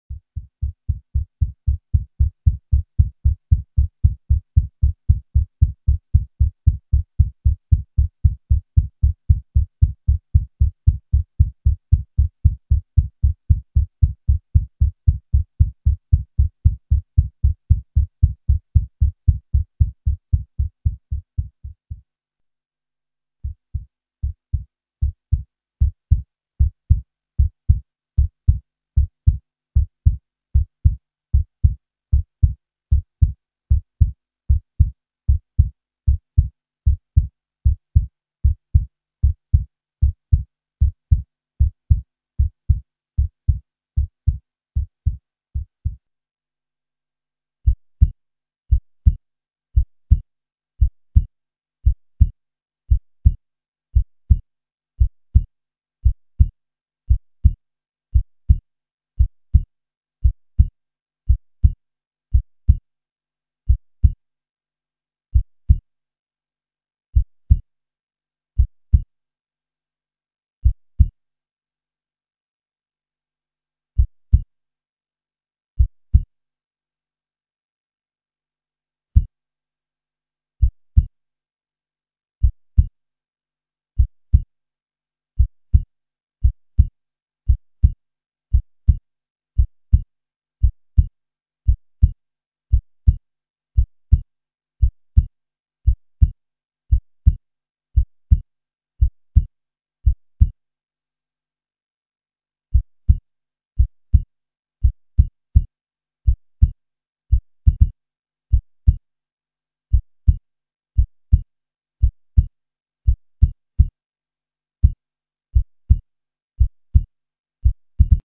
جلوه های صوتی
دانلود صدای ضربان قلب نوزاد با ریتم سریع از ساعد نیوز با لینک مستقیم و کیفیت بالا